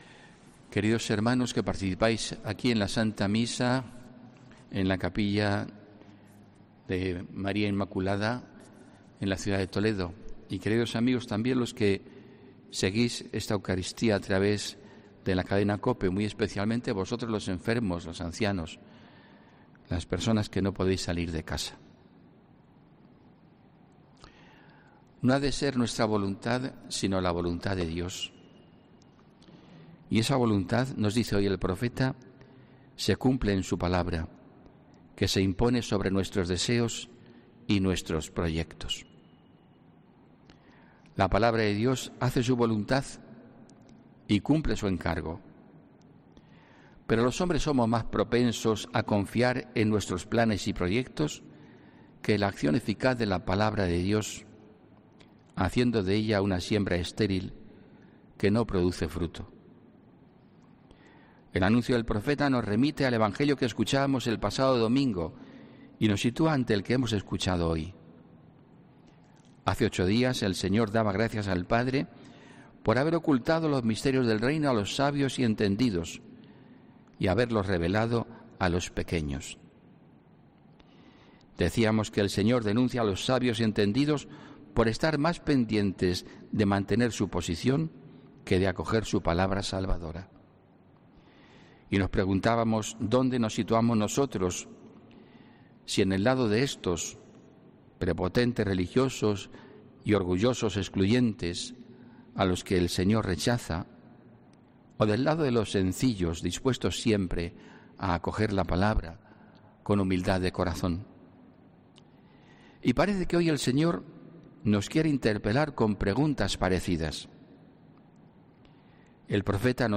HOMILÍA 12 JULIO 2020